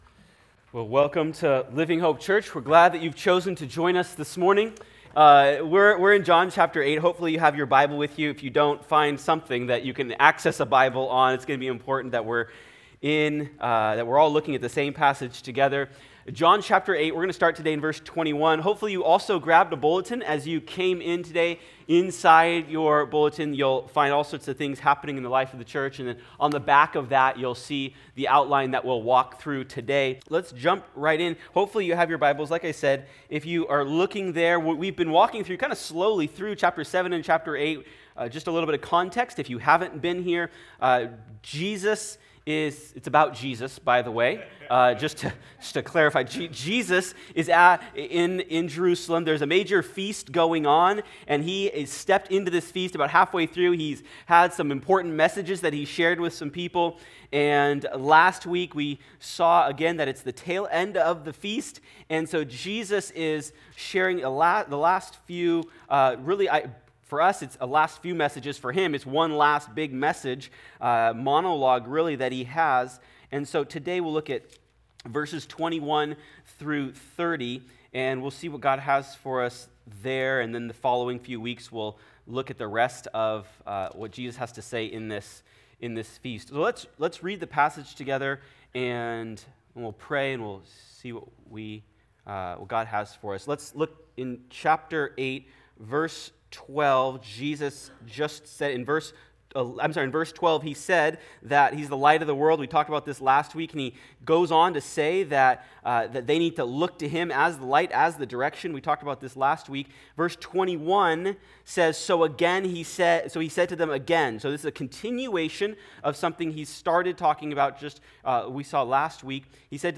In this provocative message, Jesus lays out the cost of rejecting Him and explains the only way anyone will truly be right before God. Sermon Notes:Coming soon.